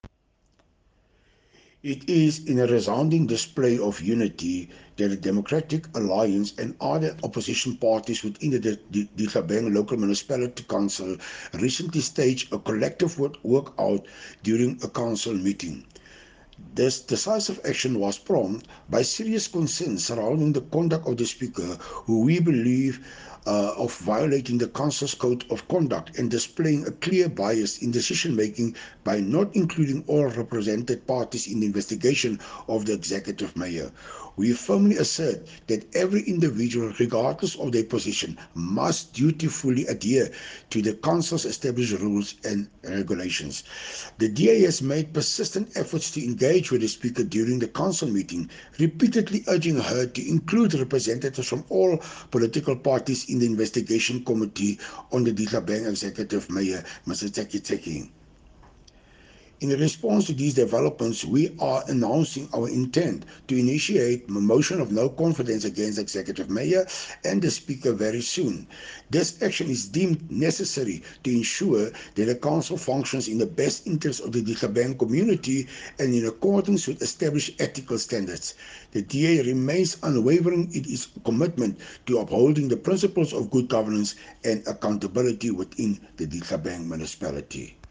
Afrikaans soundbites by Cllr Hilton Maasdorp and